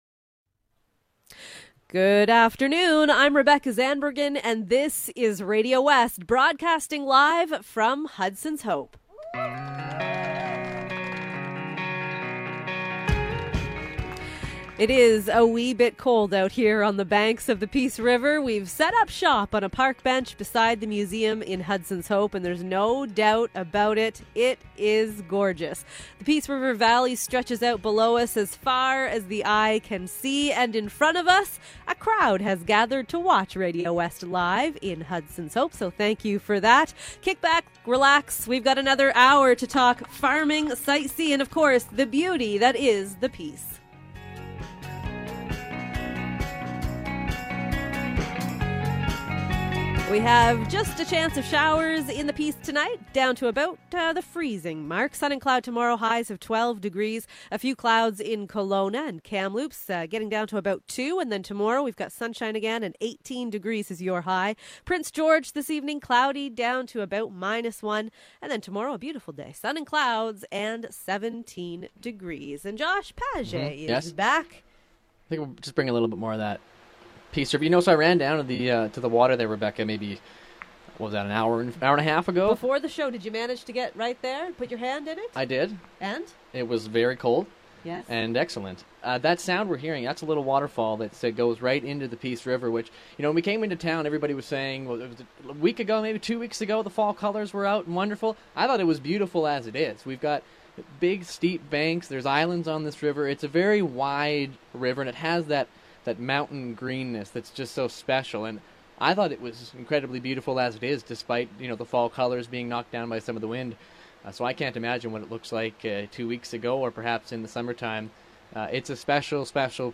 The second half of the program remains mainly focused on the Site C Dam. We hear from farmers and our political analyst, as well as plenty of other voices. All songs have been removed.